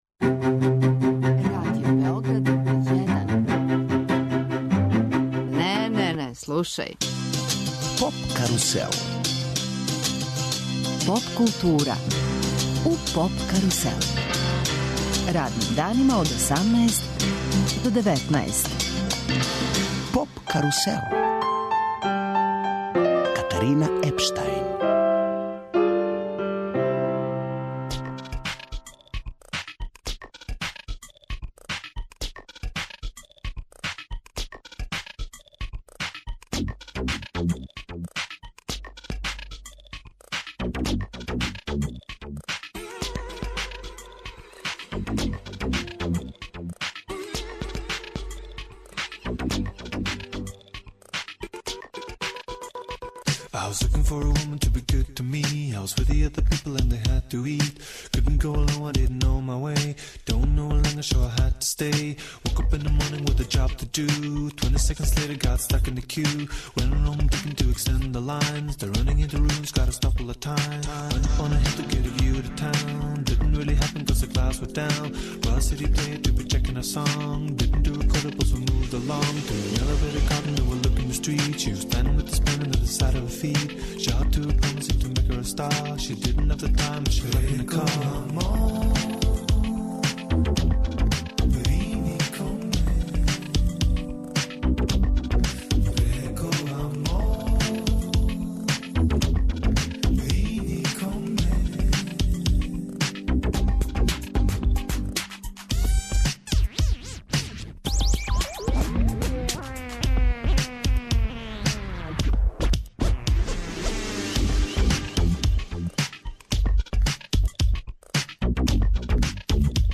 Гост емисије биће Erlend Øye, једна половина прослављеног норвешког дуа Kings of Convenience, познат нашој публици и са пројектом The Whitest Boy Alive, који наступа 18. јуна у сали Американа Дома омладине Београда.